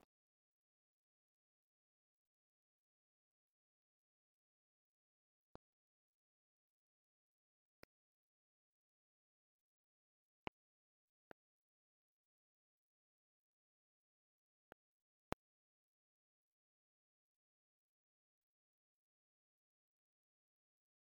Leticia, Amazonas
Adivinanza Jagagɨ Jitoma. (Casete original
Este canto hace parte de la colección de cantos del ritual Yuakɨ Murui-Muina (ritual de frutas) del pueblo Murui